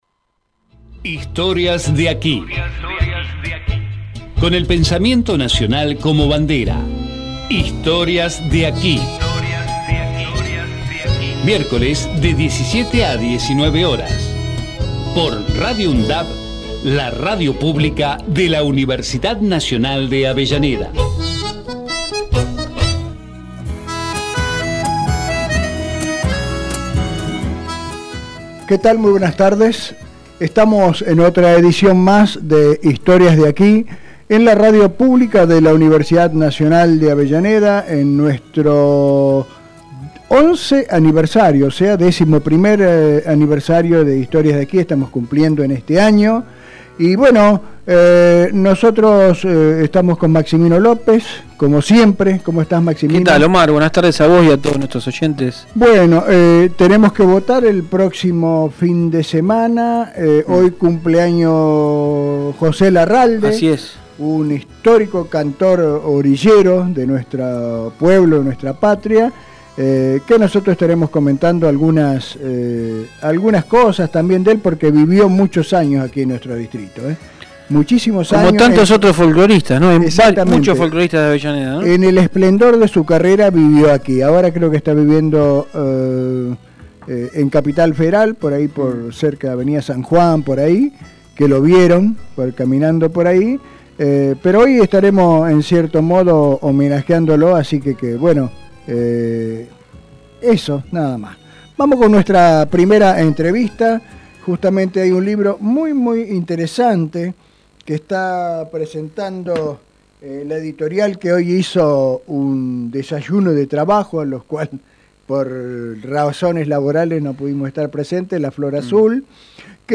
Historias de aquí Texto de la nota: Historias de aquí Con el pensamiento nacional como bandera. Música regional, literatura y las historias que están presentes en la radio.